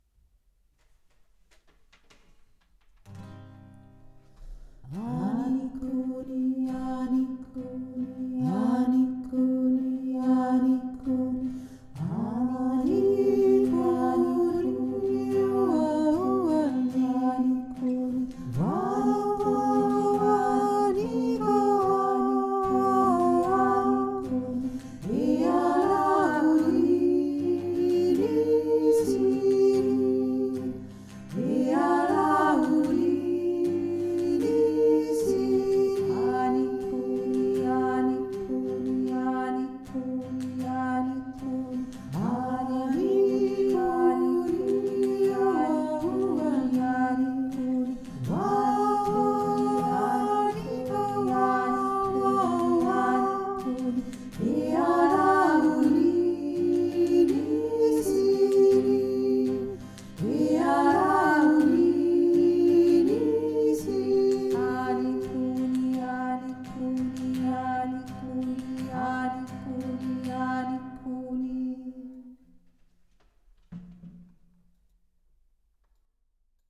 Ani kuni (indianscher Chant)